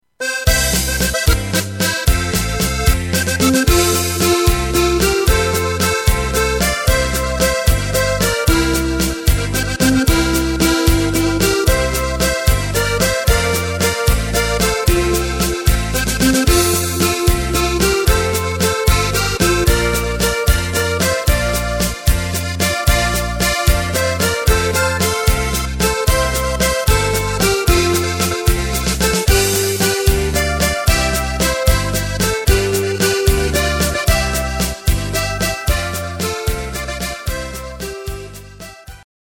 Takt:          3/4
Tempo:         225.00
Tonart:            F
Walzer aus dem Jahr 2008!